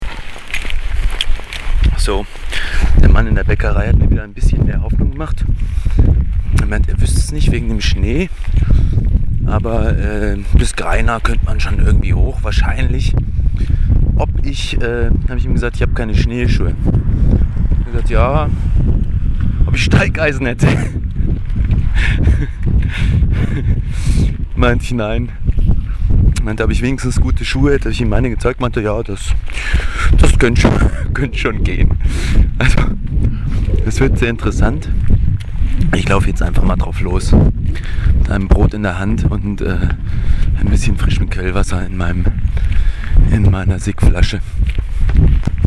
Ein kleiner Audiobericht meiner Alpenpassüberquerung mit Zelt und Krempel.